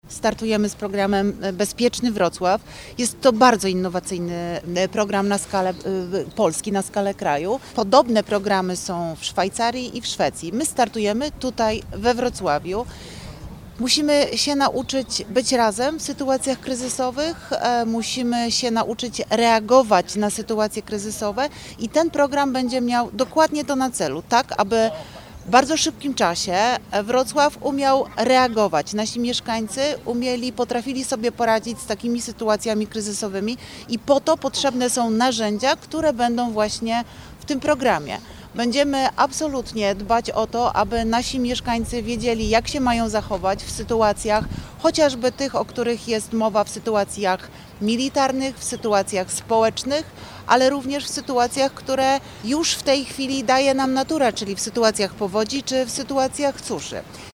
– Budowanie trwałej odporności społeczeństwa na kryzysy wymaga przede wszystkim edukacji i szkoleń – mówiła Renata Granowska, wiceprezydent Wrocławia.